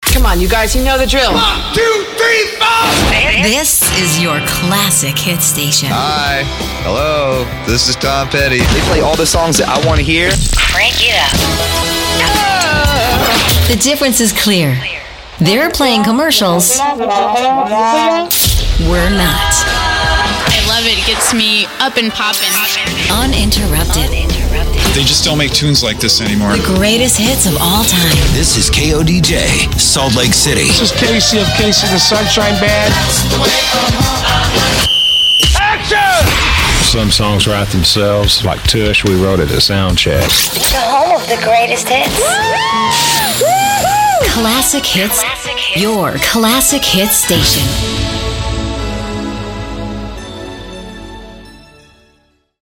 CLASSIC HITS